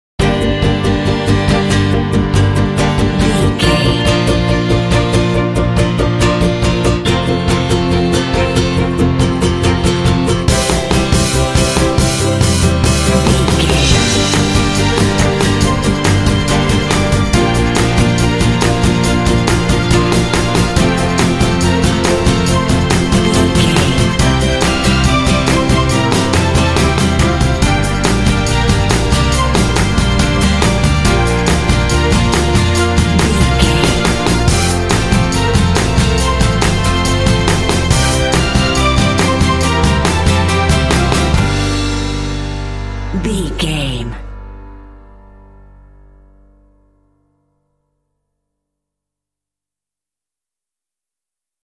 Dorian
Fast
energetic
cheerful/happy
strings
acoustic guitar
bass guitar
drums
symphonic rock
cinematic